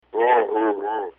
roar